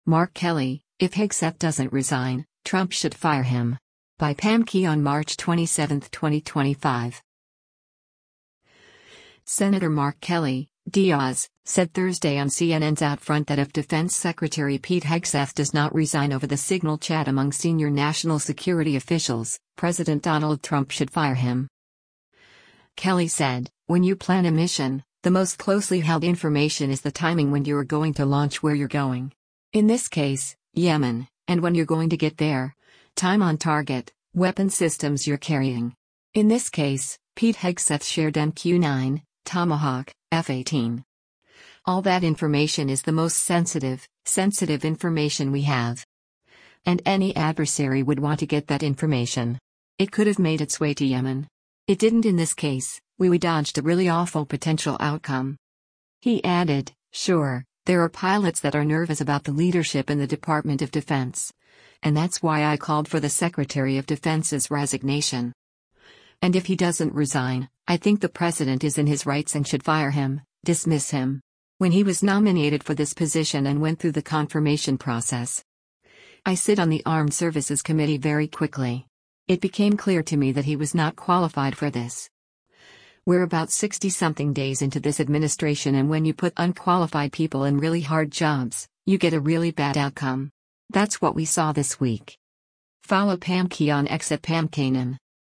Senator Mark Kelly (D-AZ) said Thursday on CNN’s “OutFront” that if Defense Secretary Pete Hegseth does not resign over the Signal chat among senior national security officials, President Donald Trump should fire him.